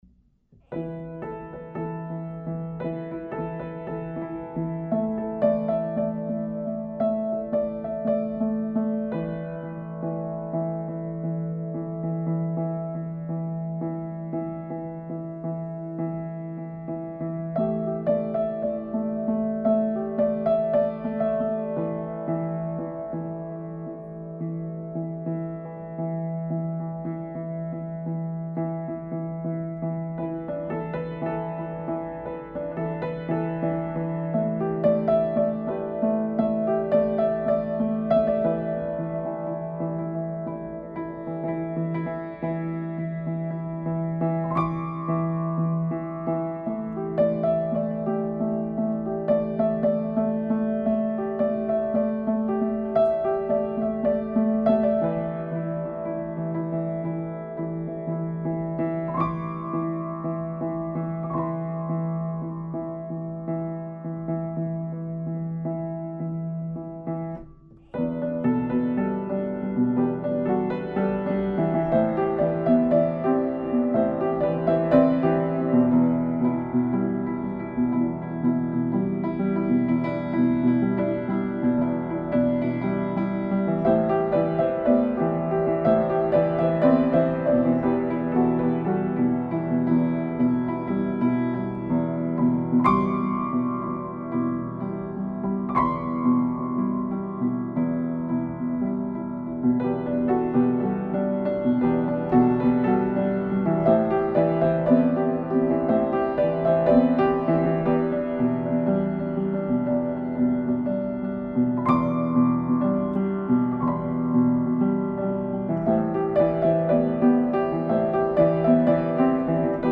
composé et interprèté sur piano acoustique Rippen 73.